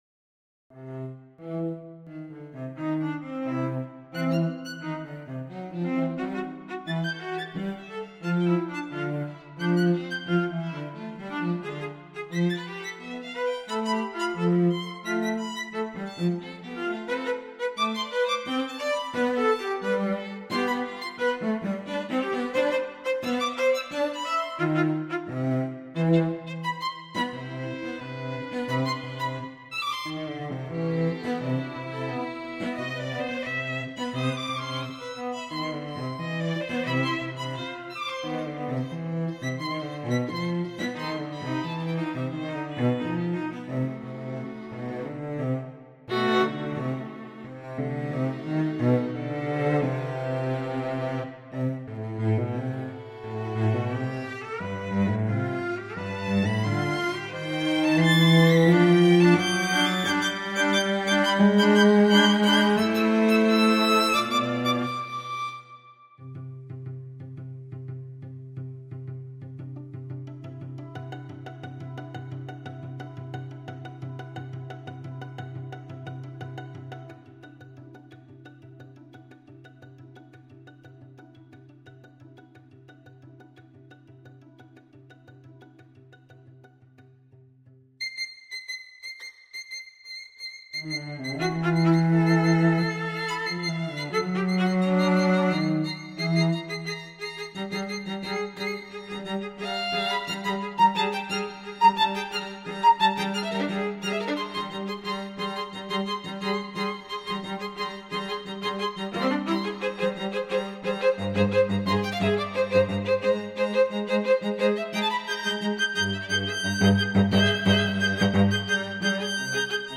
I Quartetti
The String Quartet